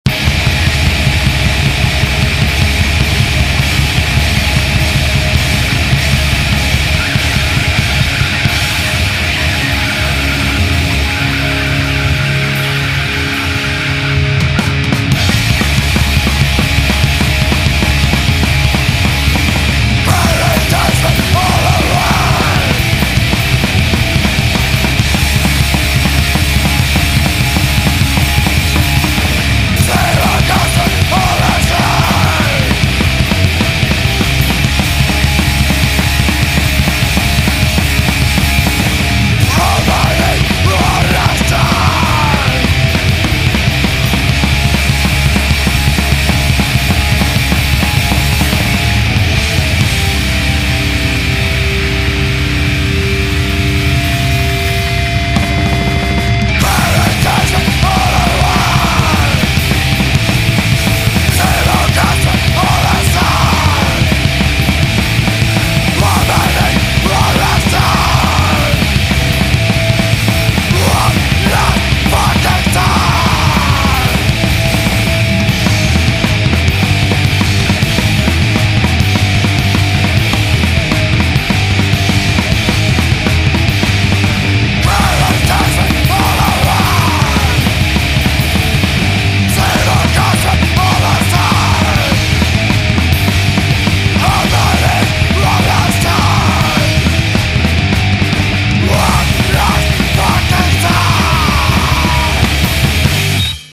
17 tracks of non-stop Crustcore/D-beat madness from the UK.
Musically this is rampant crustcore/hardcore